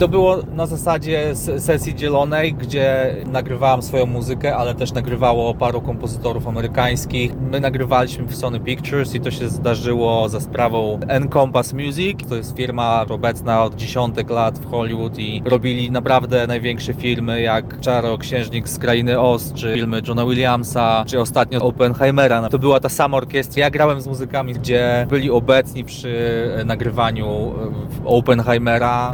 utwór symfoniczny